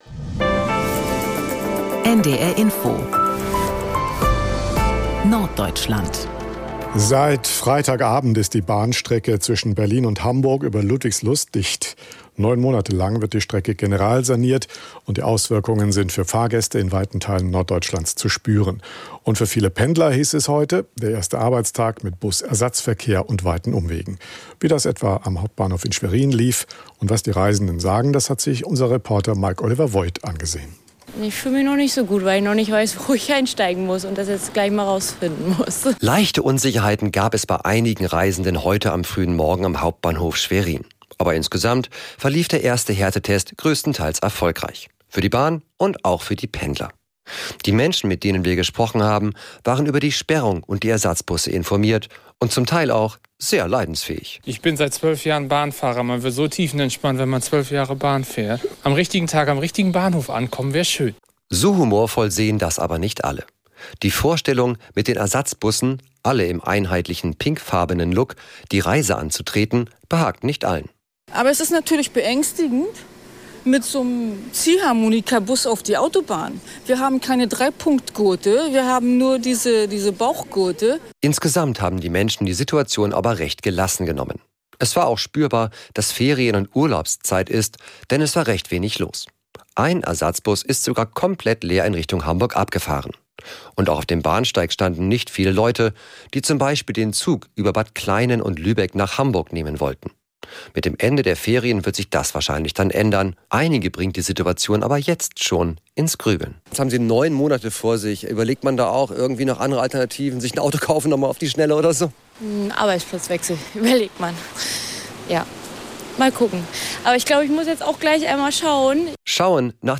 … continue reading 12 Episoden # Tägliche Nachrichten # Nachrichten # NDR Info